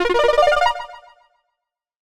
Sound effects[edit]
DKC_SNES_Extra_Life_Balloon.oga